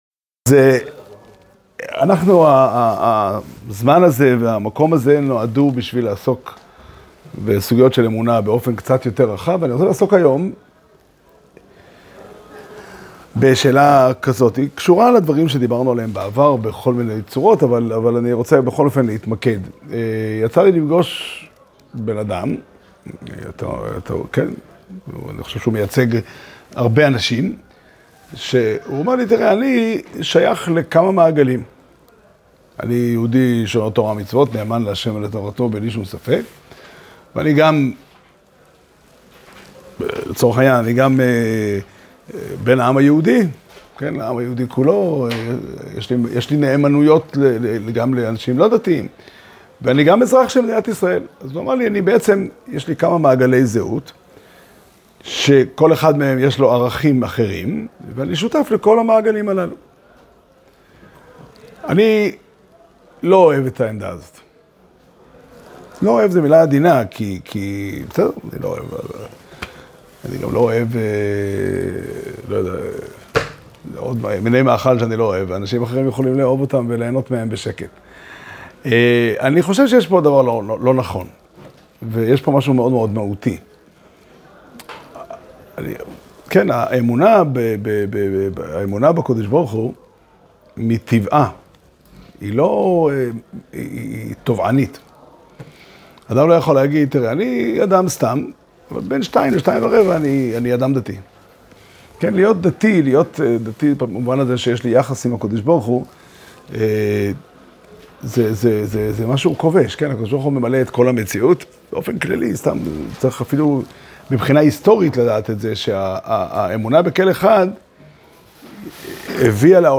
שיעור שנמסר בבית המדרש פתחי עולם בתאריך ז' שבט תשפ"ה